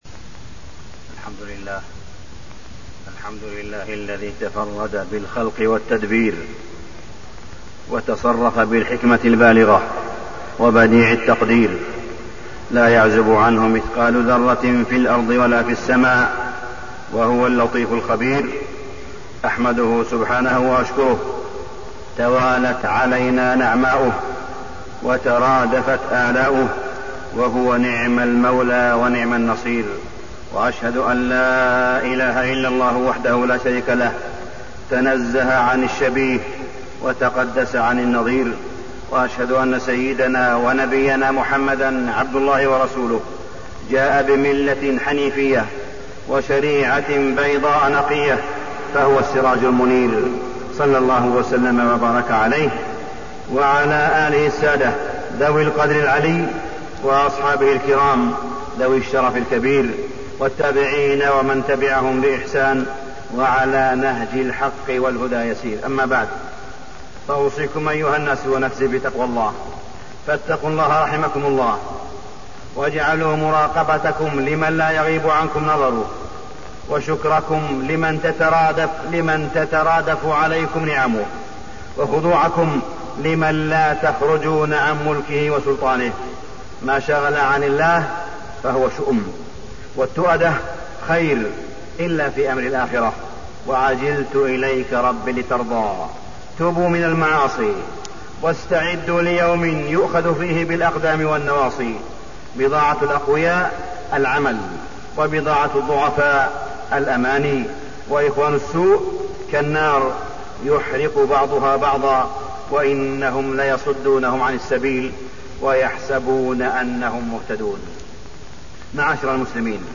تاريخ النشر ١١ محرم ١٤٣٢ هـ المكان: المسجد الحرام الشيخ: معالي الشيخ أ.د. صالح بن عبدالله بن حميد معالي الشيخ أ.د. صالح بن عبدالله بن حميد اليقين